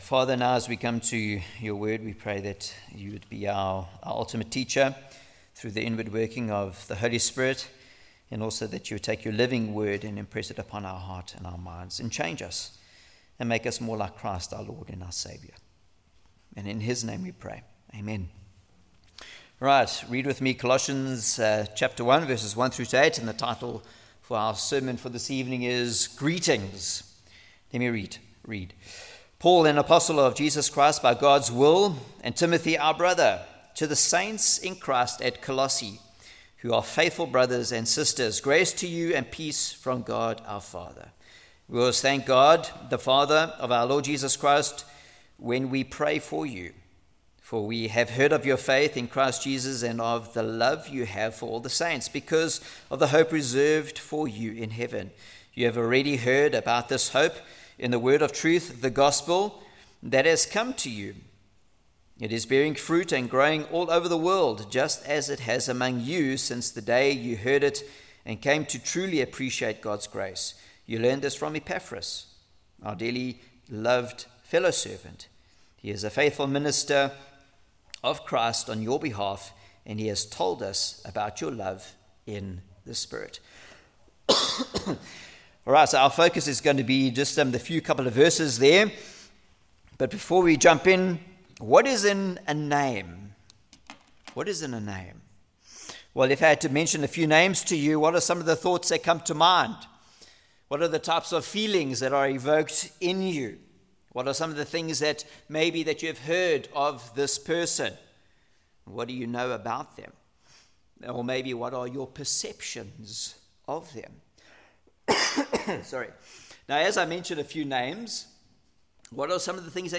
Colossians Passage: Colossians 1:1-8 Service Type: Sunday Evening From Paul To the Saints « Eternal Security What Does It Mean To Be A Christian?